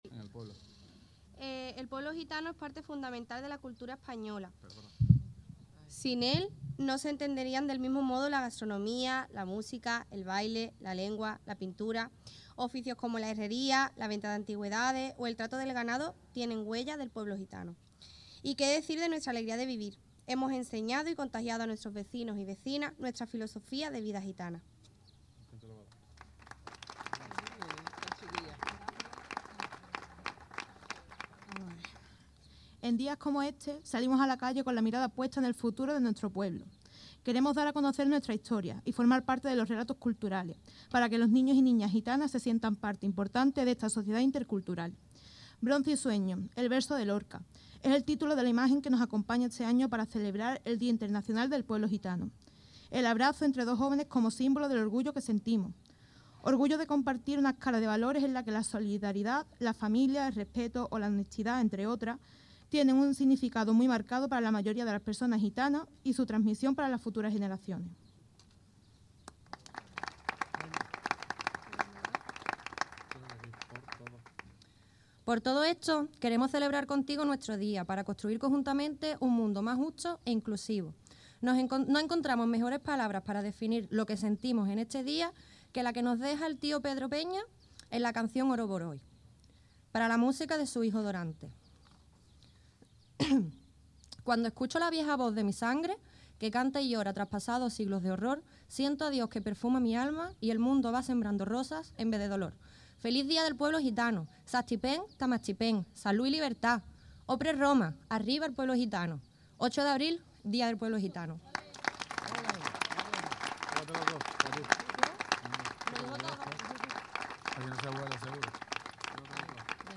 San Roque ha celebrado, un año más, el Día Internacional del Pueblo Gitano con la izada de la bandera gitana y la lectura de un manifiesto. El acto ha tenido lugar este mediodía en la Plaza de las Constituciones y se ha contado con una amplia asistencia.
BANDERA_PUEBLO_GITANO_TOTAL_DISCURSOS.mp3